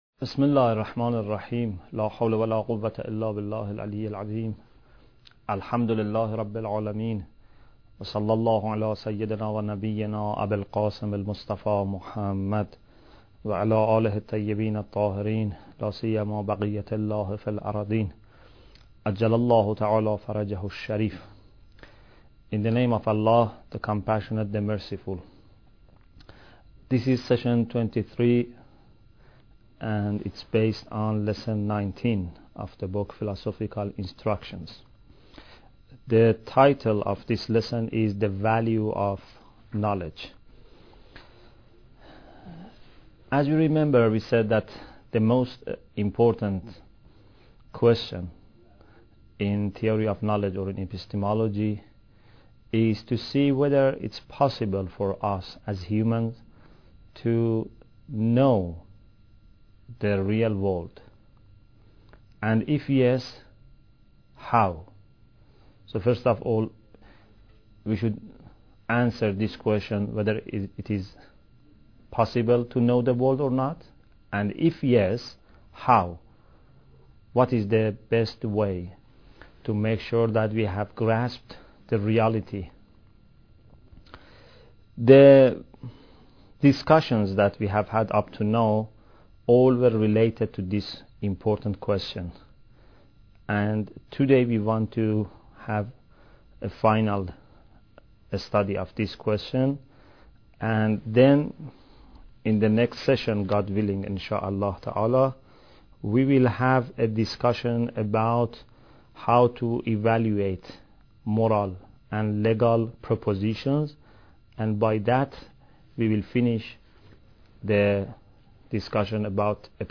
Bidayat Al Hikmah Lecture 22